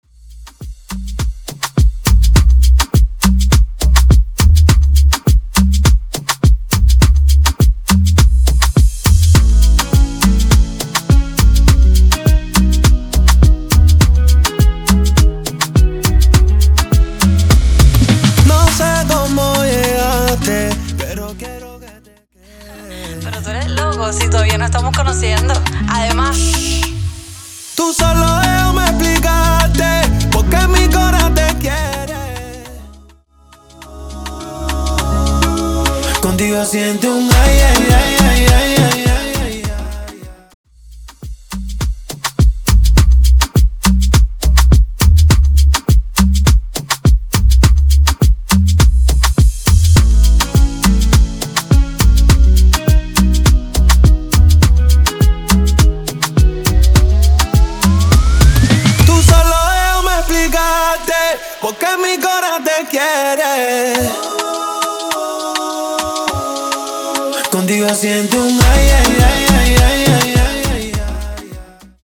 Intro Dirty, Intro Acapella Dirty